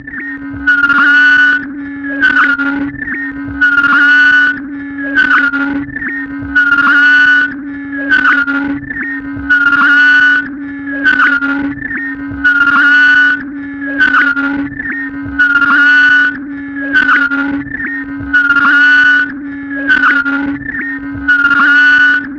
卷对卷磁带循环录音的反馈 " Bleary Chorp
描述：尖锐的高音调向上反馈的鸣叫声，记录在1/4" 磁带上，并进行物理循环
Tag: 投式 啁啾 反馈 刺耳 胶带环